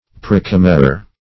Meaning of praecommissure. praecommissure synonyms, pronunciation, spelling and more from Free Dictionary.
Search Result for " praecommissure" : The Collaborative International Dictionary of English v.0.48: Praecommissure \Pr[ae]*com"mis*sure\, n. [Pref. pr[ae] + commissure.]